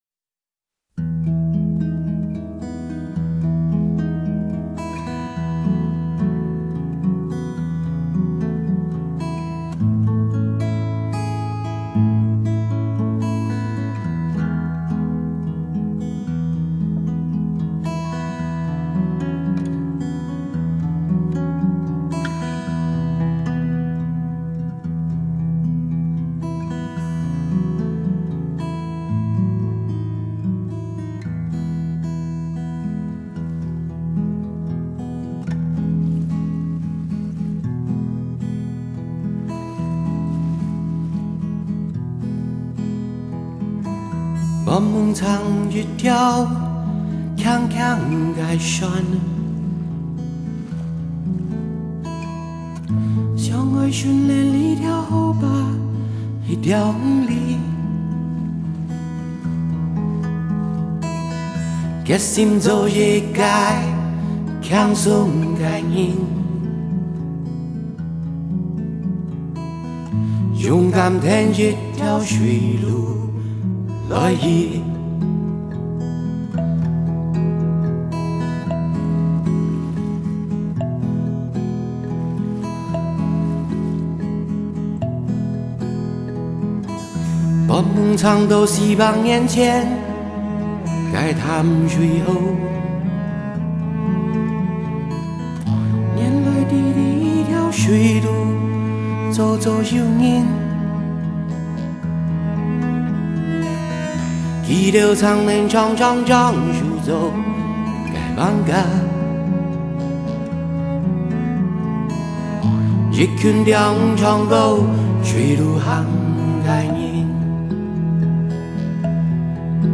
【客家語專輯】